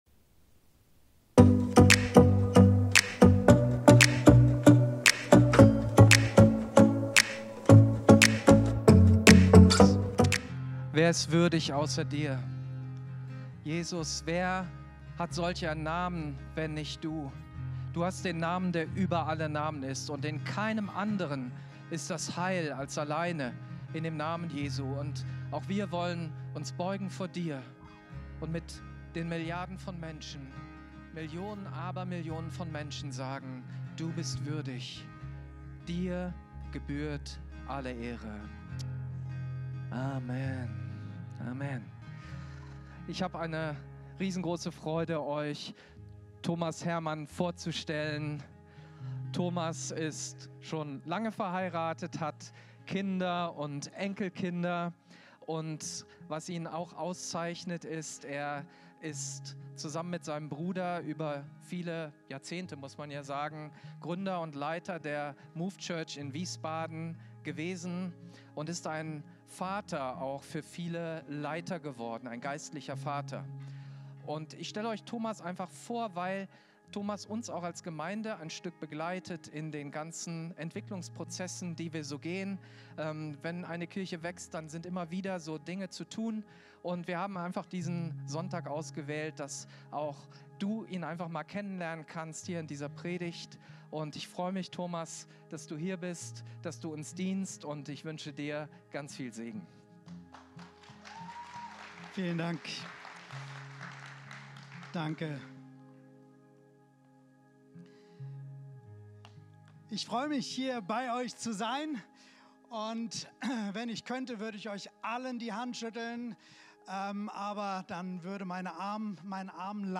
Live-Gottesdienst aus der Life Kirche Langenfeld.